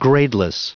Prononciation du mot gradeless en anglais (fichier audio)
Prononciation du mot : gradeless